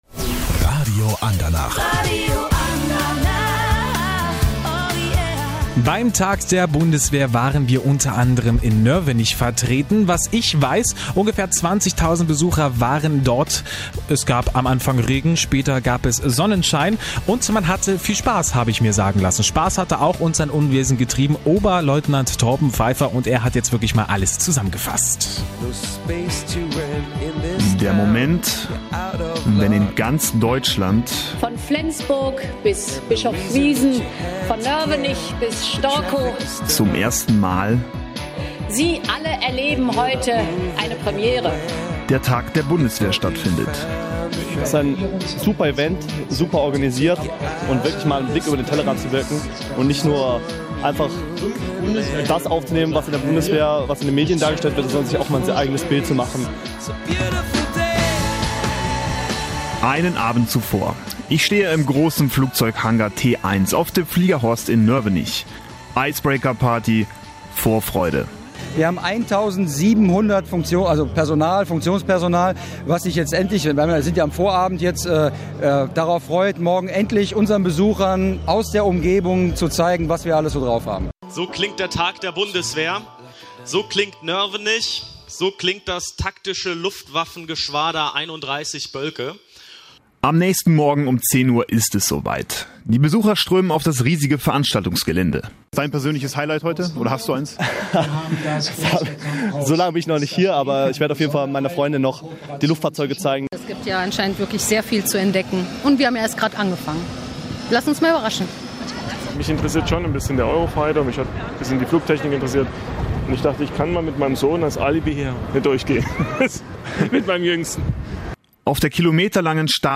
Das Taktische Luftwaffengeschwader 31 begrüßte allein 20.000 Gäste auf dem Fliegerhorst in Nörvenich. Großgerät der Luftwaffe, ein buntes Bühnenprogramm und viele weitere Attraktionen - hier zu hören in unserer Erlebnis-Reportage vom Tag der Bundeswehr in Nörvenich.